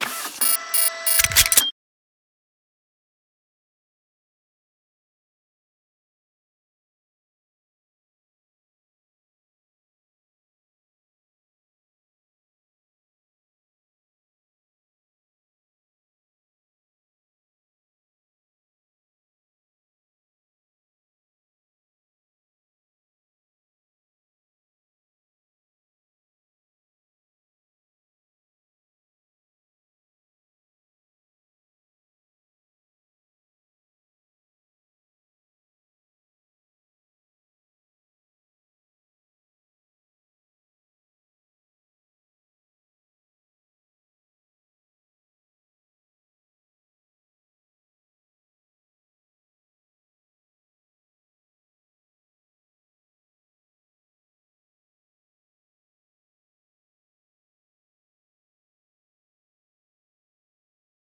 scanner.wav